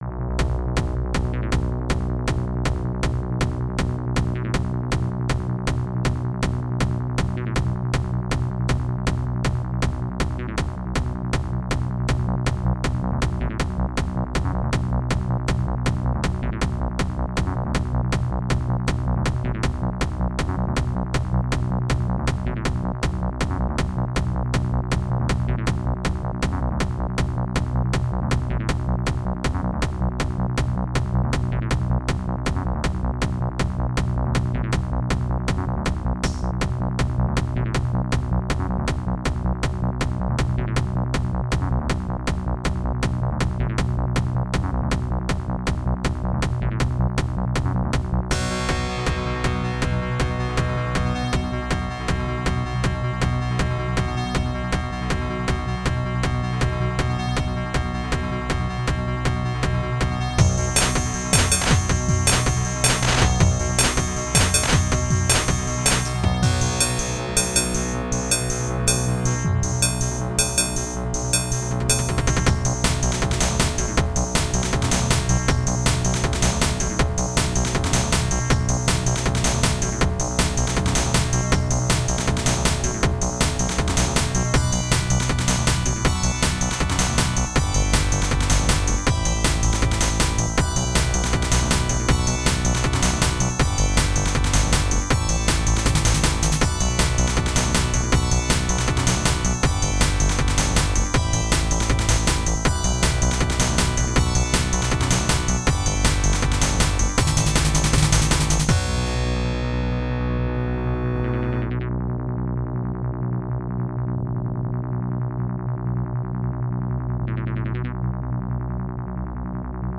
chiptune
Music / Game Music